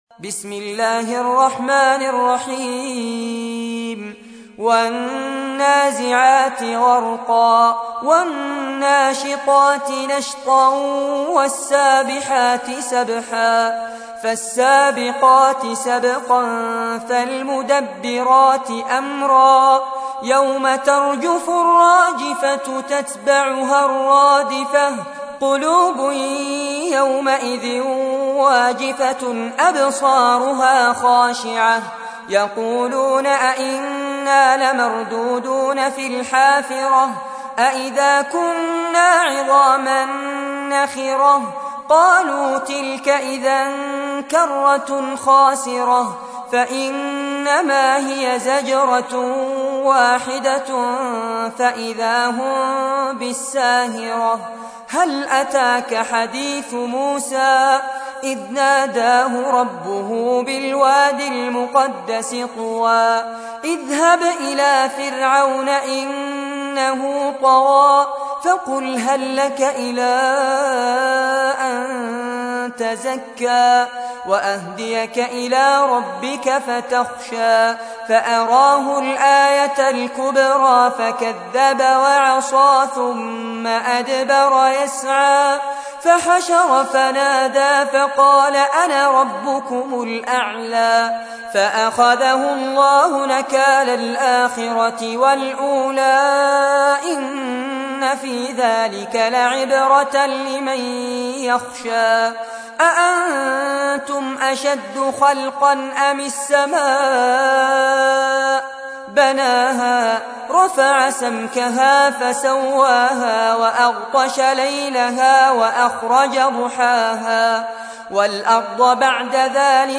تحميل : 79. سورة النازعات / القارئ فارس عباد / القرآن الكريم / موقع يا حسين